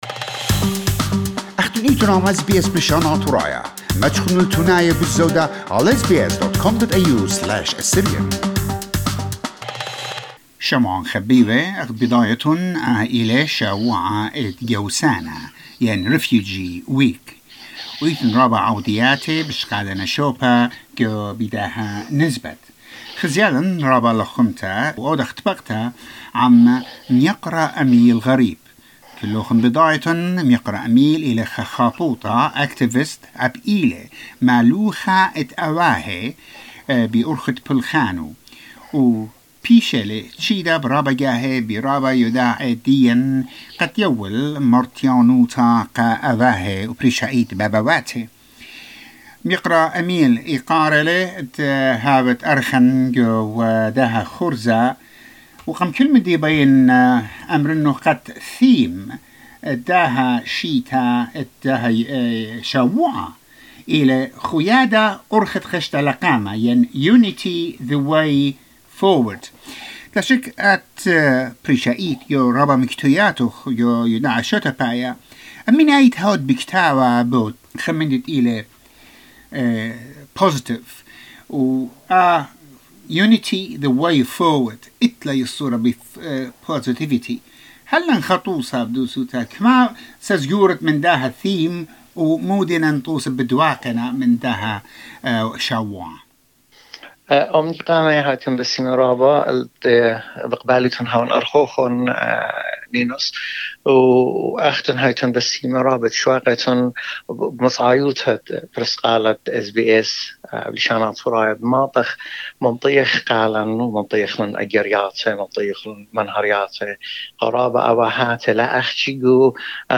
In this Refugee Week segment, we interviewed